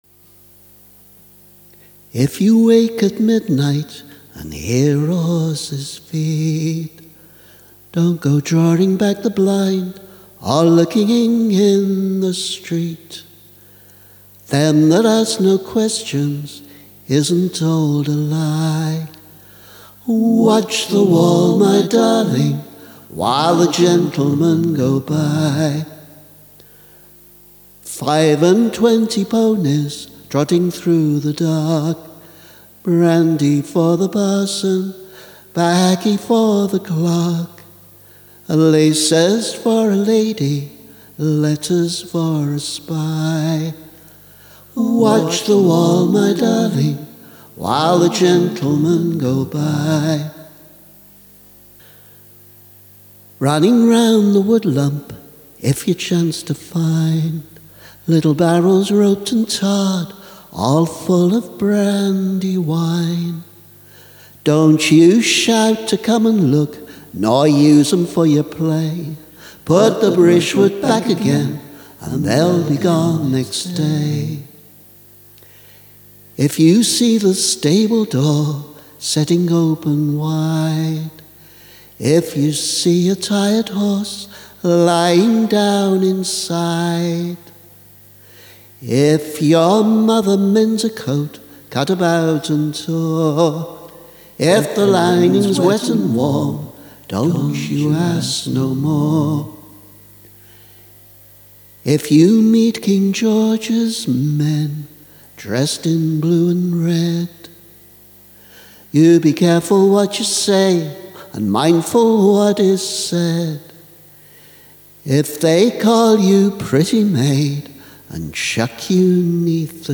Mastered version